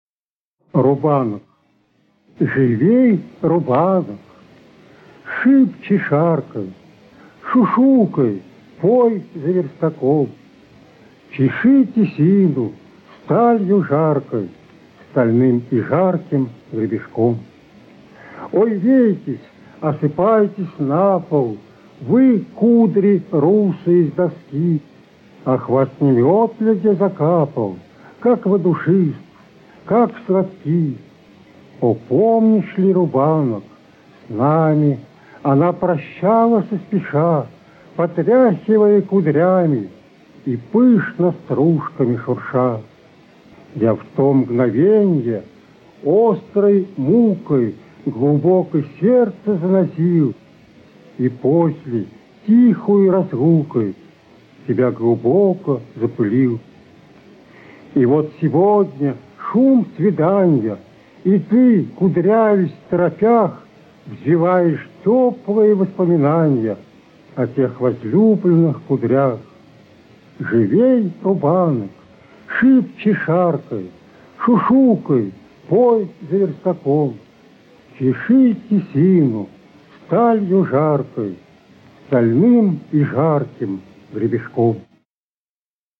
1. «Василий Казин – Рубанок (читает автор)» /
Kazin-Rubanok-chitaet-avtor-stih-club-ru.mp3